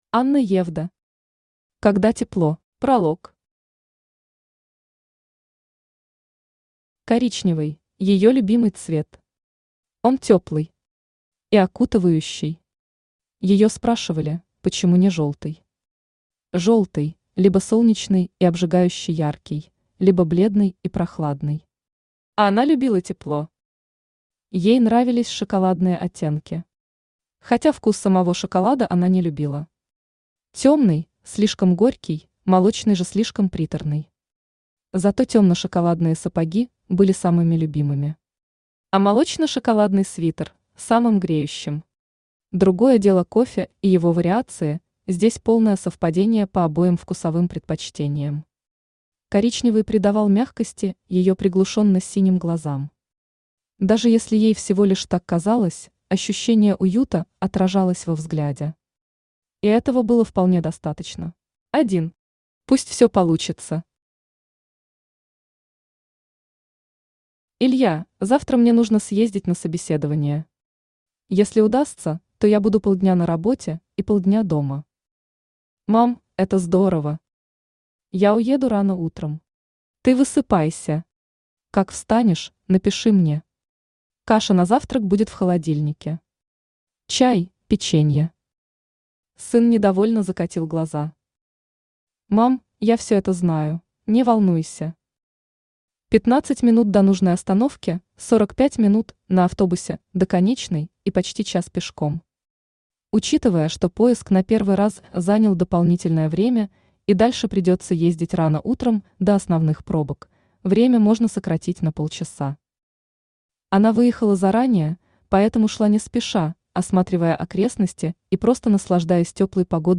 Аудиокнига Когда тепло | Библиотека аудиокниг
Aудиокнига Когда тепло Автор Анна Евдо Читает аудиокнигу Авточтец ЛитРес.